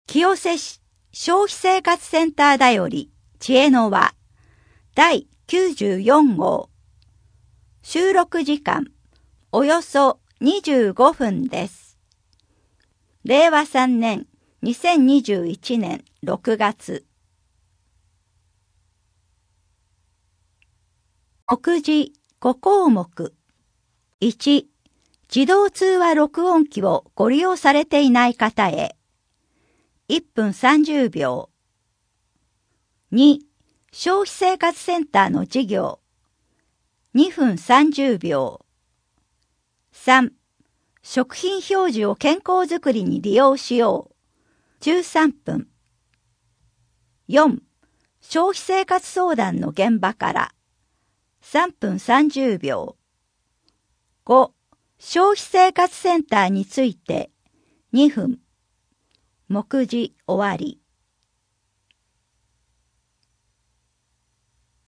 栄養成分表示の内容 エネルギーがどれくらいか確かめてみましょう 原材料名の表示方法 栄養表示を活用しよう 減塩に挑戦 高齢者の低栄養予防 バランスの良い献立の工夫 4面 消費生活相談の現場から 消費生活相談の現場から 引っ越しトラブル 消費生活センターについて 声の広報 声の広報は清瀬市公共刊行物音訳機関が制作しています。